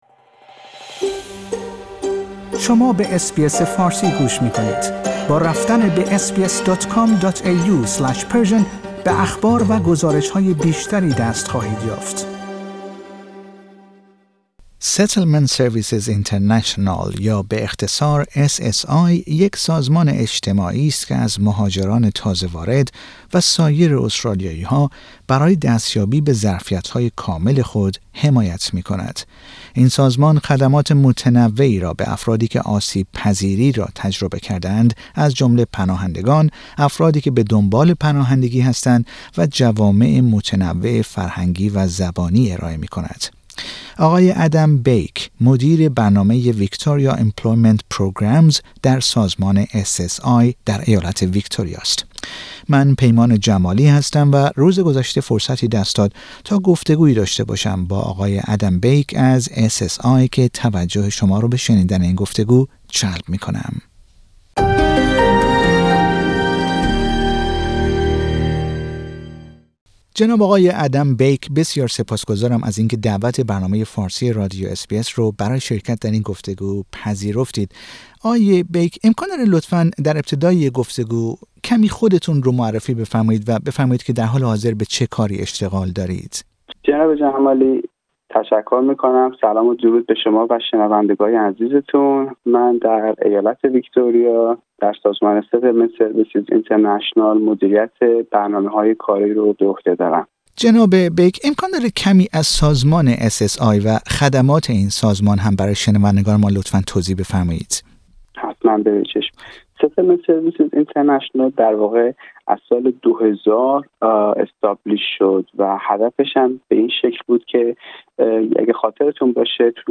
در گفتگو با رادیو اس بی اس فارسی درباره این خدمات سخن می گوید.